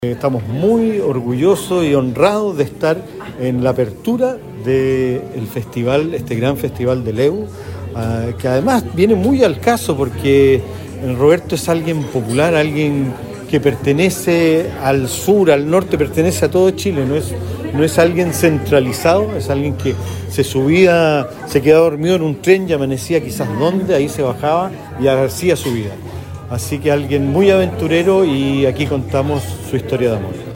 En conferencia de prensa realizada en el Hotel Araucano, en el centro penquista, se dieron a conocer los principales hitos de esta edición especial, marcada por el fortalecimiento de la industria audiovisual regional y nacional, con el apoyo tanto del sector público como de la empresa privada.